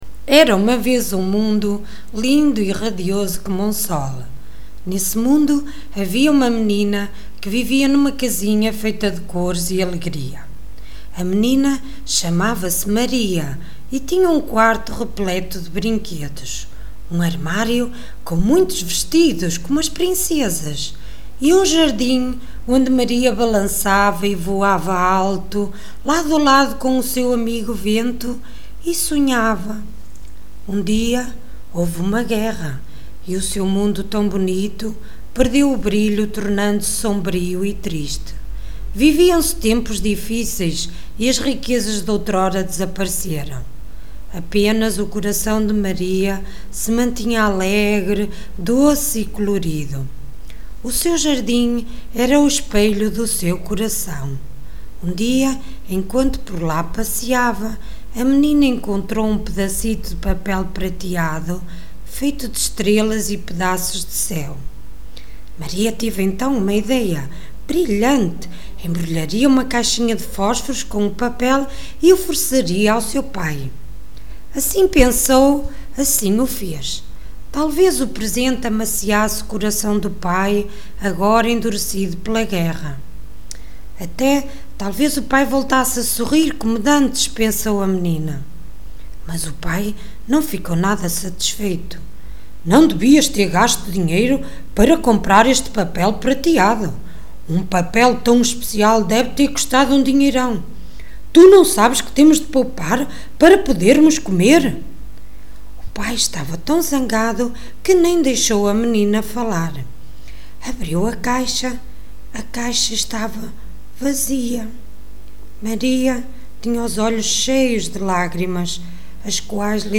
Biblioteca Municipal Ferreira de Castro Semana da Leitura 2008 - Um minuto pela Leitura
Leitura do Pré-Escolar